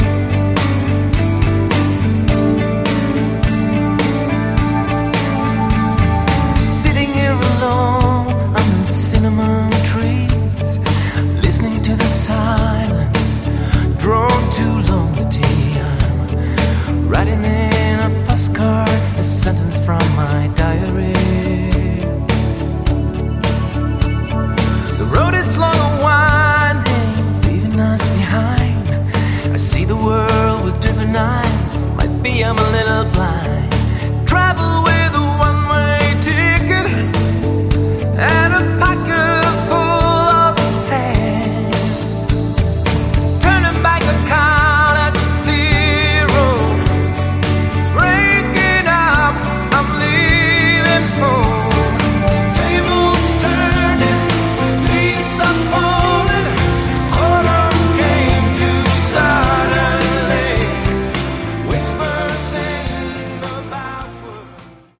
Category: AOR
keyboards, piano